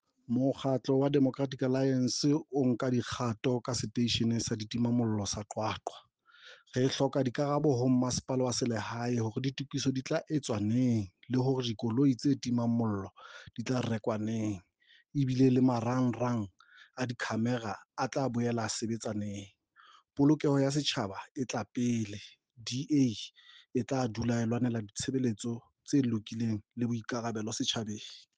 Sesotho soundbites by Cllr Paseka Mokoena and